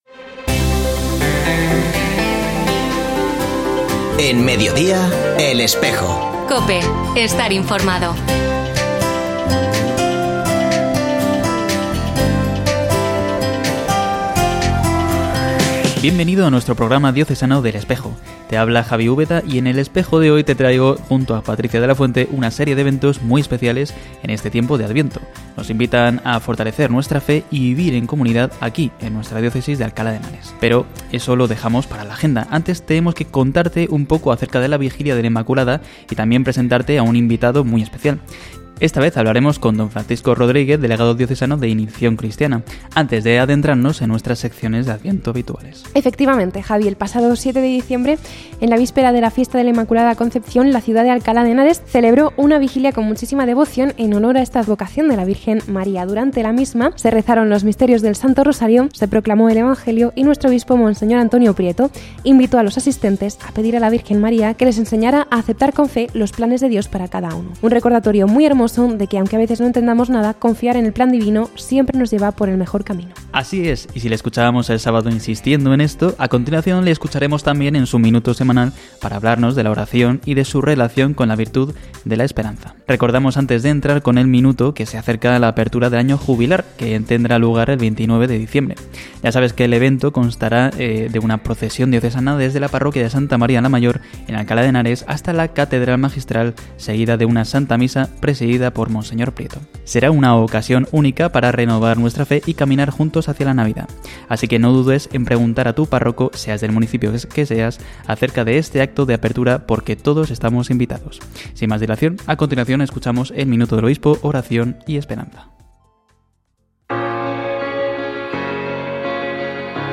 Escucha otras entrevistas de El Espejo de la Diócesis de Alcalá
Se ha vuelto a emitir hoy, 13 de diciembre de 2024, en radio COPE. Este espacio de información religiosa de nuestra diócesis puede escucharse en la frecuencia 92.0 FM, todos los viernes de 13.33 a 14 horas.